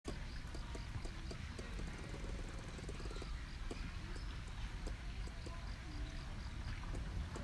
この声は少し警戒しているときの声です。
「コッコッコッコッ　ココココココ」という声がきこえます。